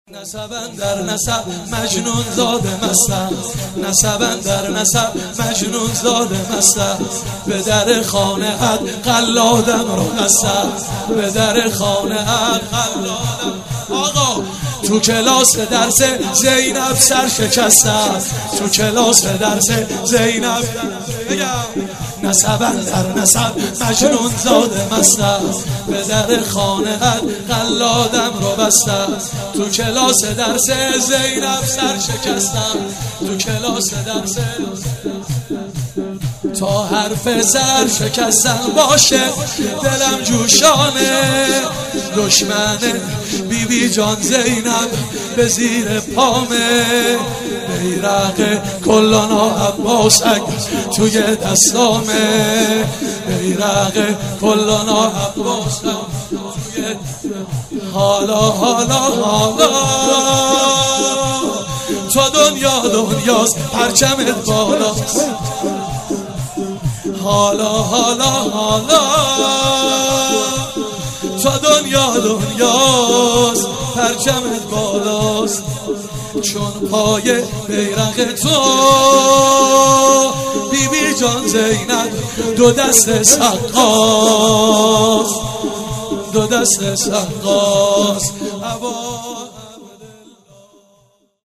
مناسبت : اربعین حسینی
قالب : شور